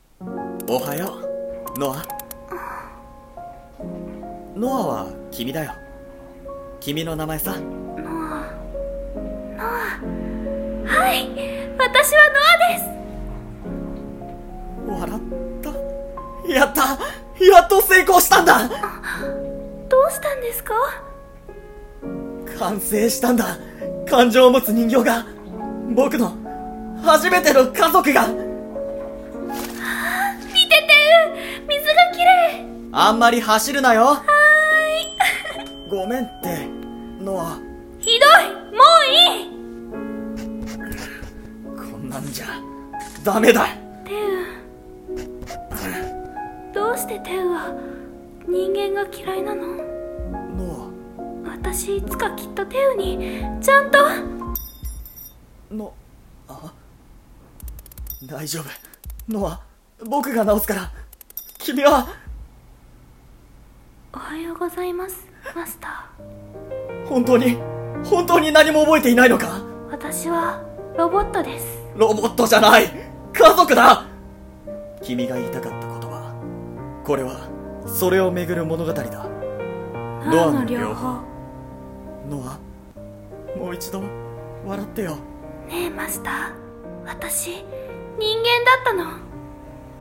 【映画予告風声劇】ノアの療法